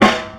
• Dope Snare Drum Sound G# Key 422.wav
Royality free steel snare drum sound tuned to the G# note. Loudest frequency: 1346Hz
dope-snare-drum-sound-g-sharp-key-422-PkL.wav